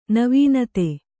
♪ navīnate